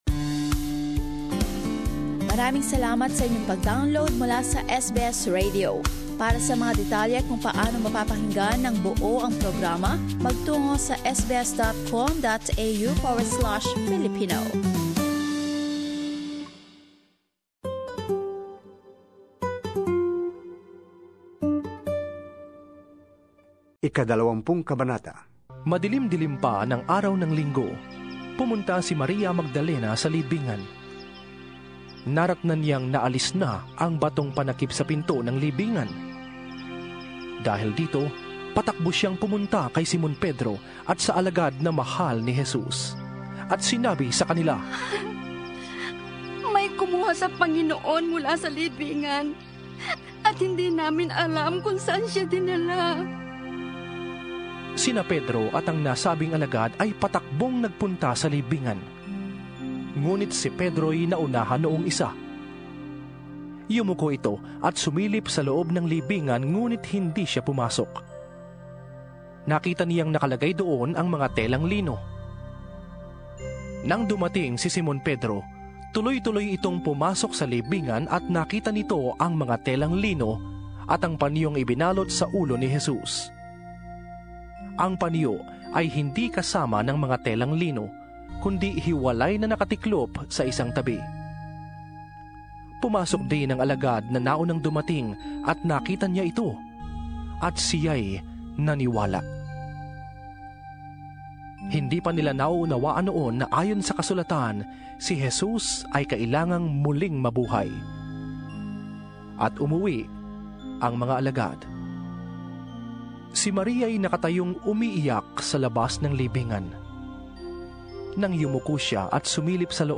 Narito ang ikatlong bahagi ng dramatisasyon mula sa Philippine Bible Society, na isinalaysay ang kwento ng resureksyon ng Panginoong Hesukristo.